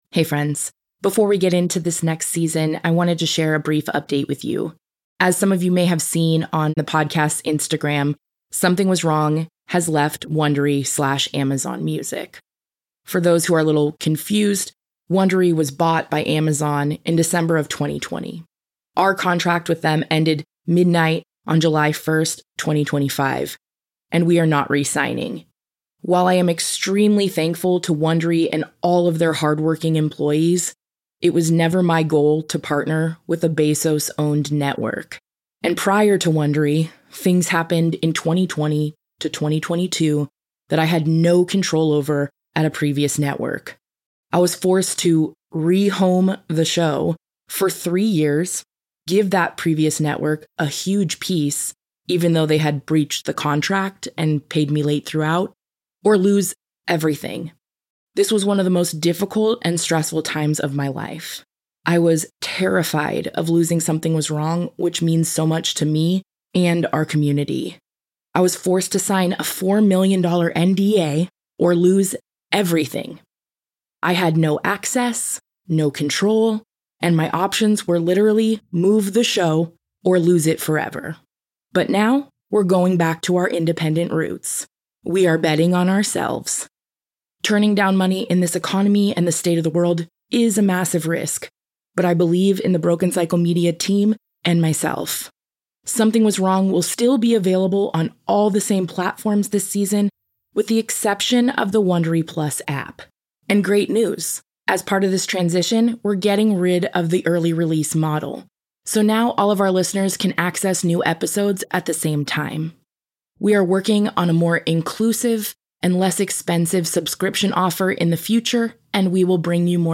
Season 24 of Something Was Wrong shares the harrowing truths of institutional child abuse through powerful interviews with survivors of wilderness therapy programs, therapeutic boarding schools, and other institutions around the world.